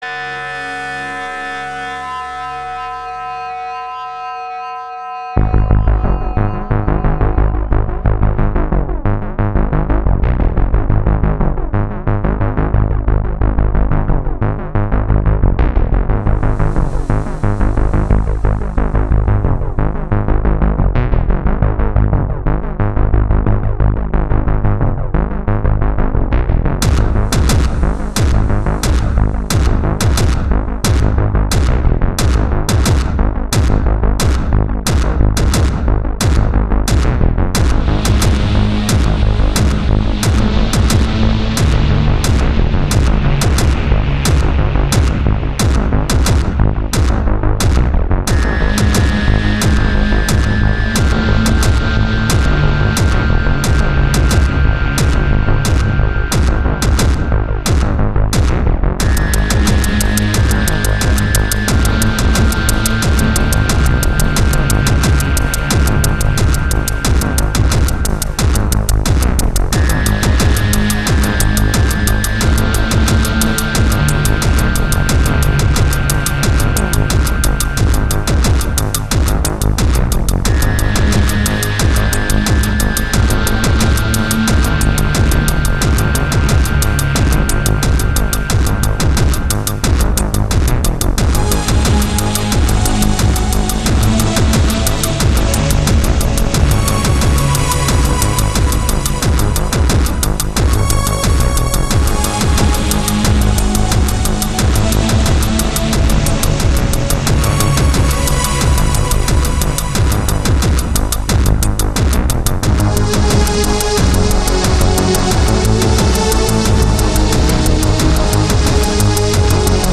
Music track mp3
Very cool; dark and brooding.
Apart from the fact it definitely sounds unfinished, it has a very sexy bassline and beatv Very Happy , it bears close resemblance to Harsh EBM.
it bears close resemblance to Harsh EBM.